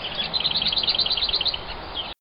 Lesser Whitethroat
rattling song.
LesserWhitethroat.ogg